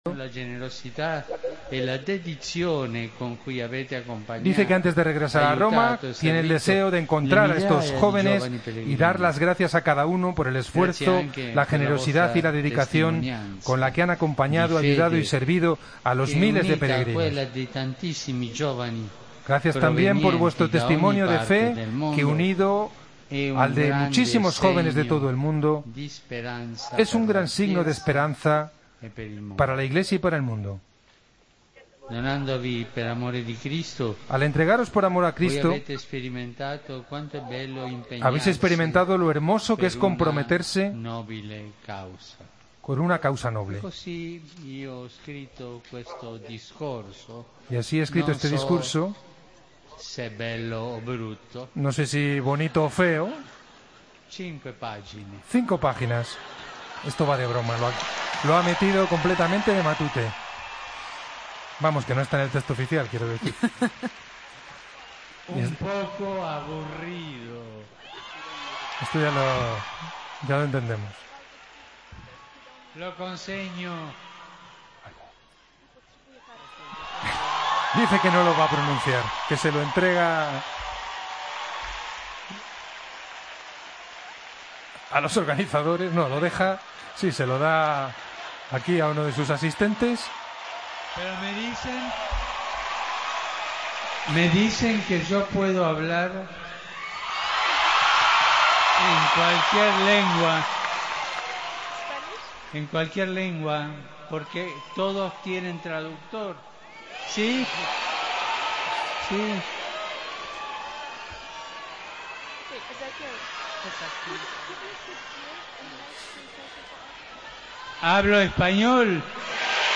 El Papa se despide de los jóvenes de la JMJ de Cracovia
AUDIO: Escucha al Papa clausurando la Jornada Mundial de la Juventud